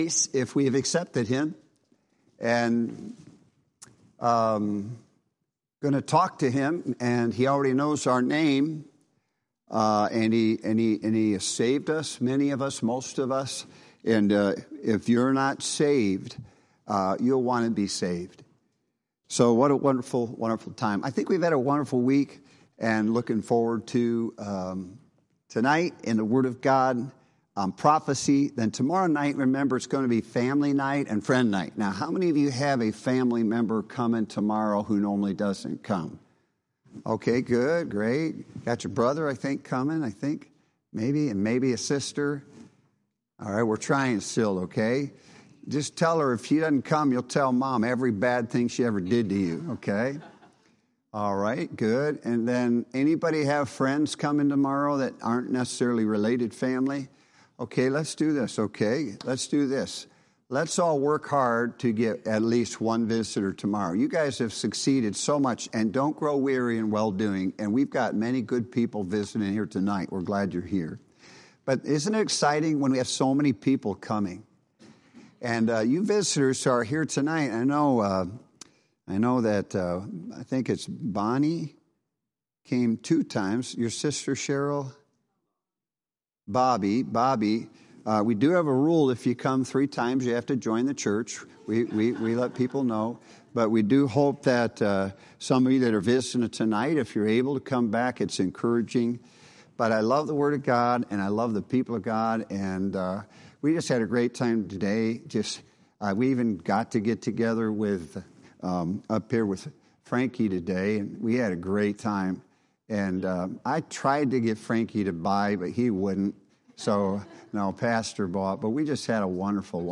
Thursday of Revival Services